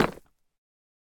Minecraft Version Minecraft Version snapshot Latest Release | Latest Snapshot snapshot / assets / minecraft / sounds / block / deepslate_bricks / step1.ogg Compare With Compare With Latest Release | Latest Snapshot
step1.ogg